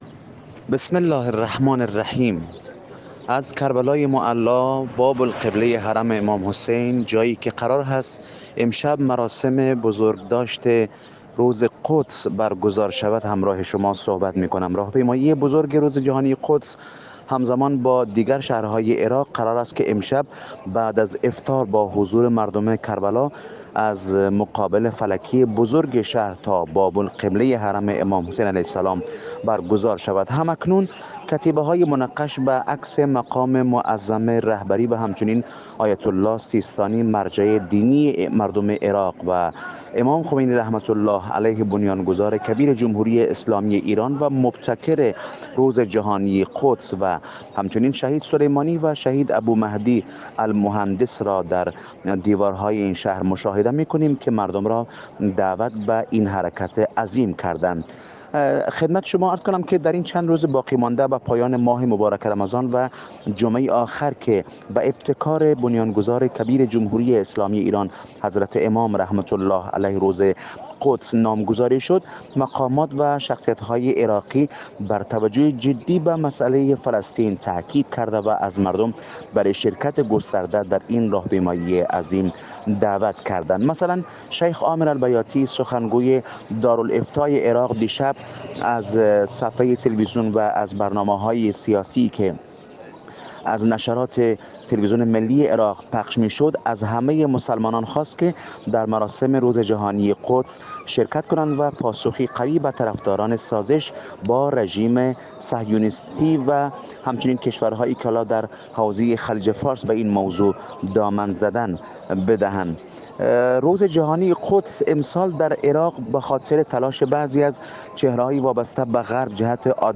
خبر / غرب آسیا